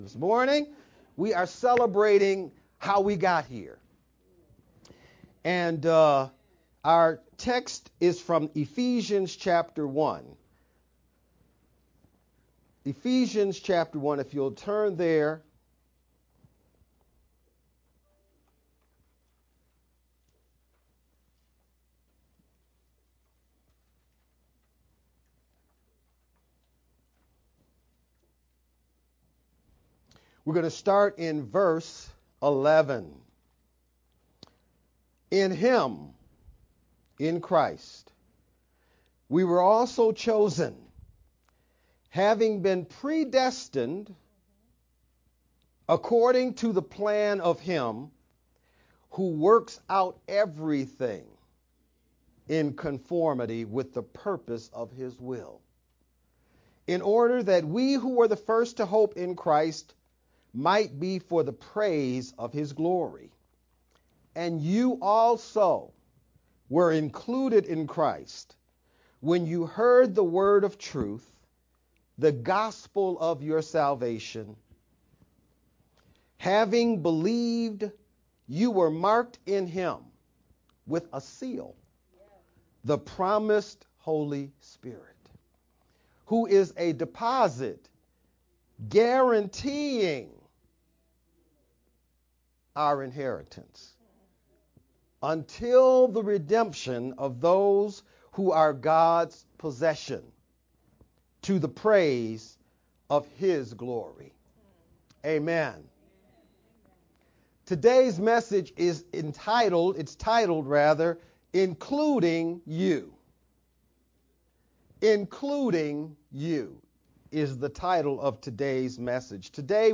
Feb-18th-Sermon-only1_Converted-CD.mp3